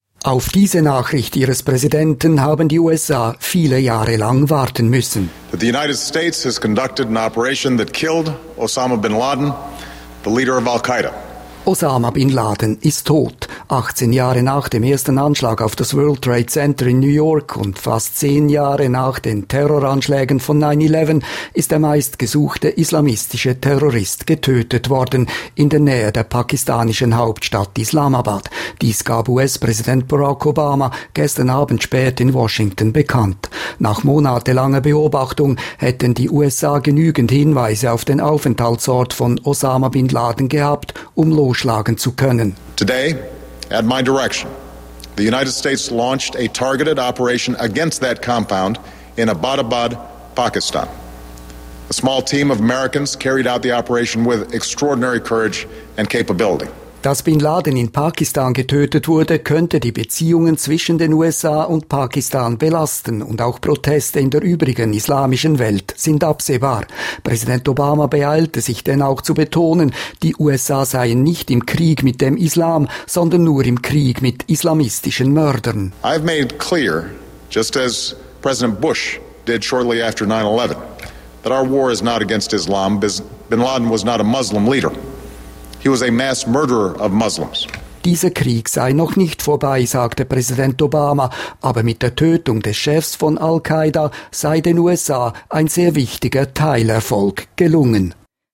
SR DRS: US-Präsident Obama erklärt den Tod von Bin Laden